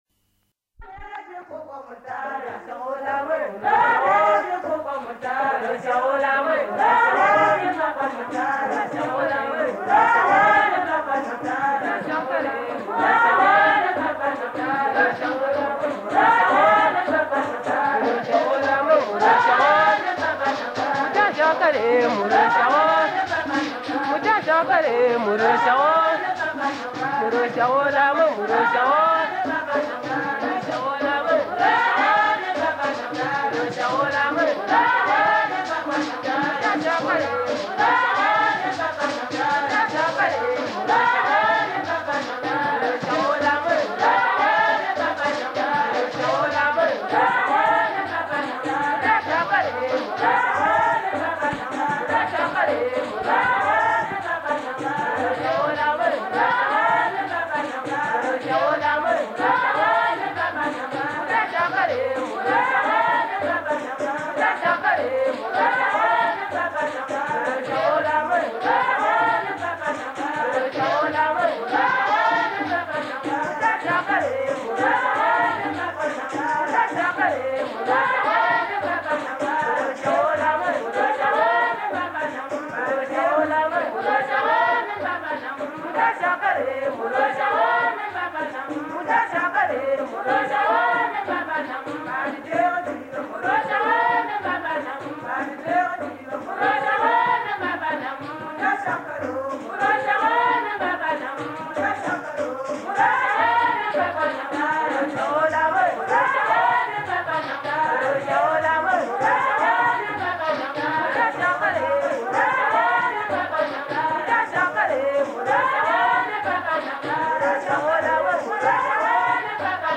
Kím Mwa (Choir) | World of Ngas